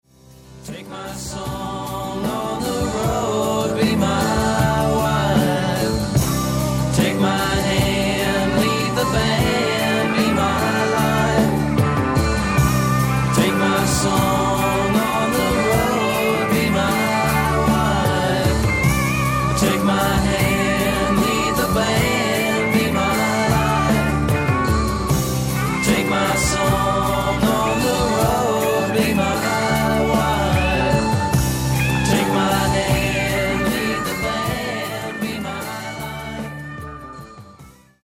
AMERICAN ROCK